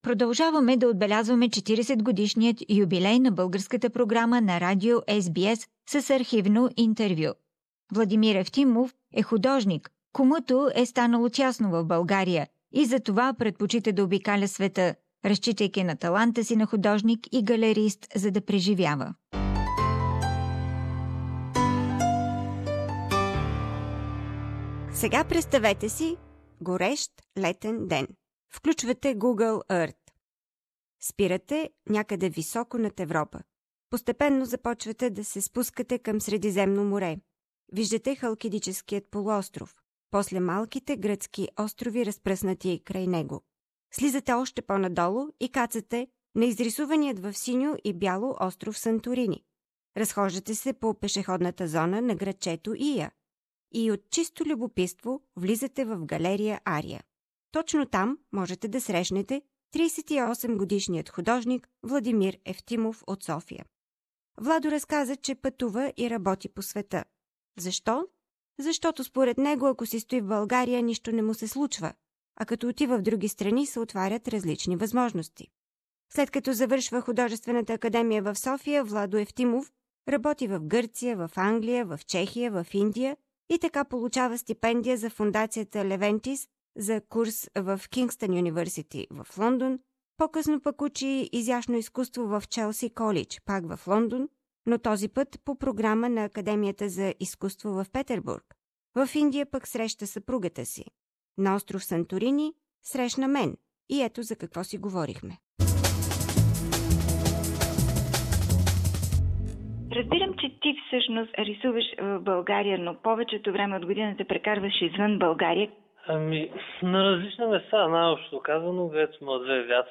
Interview from Santorini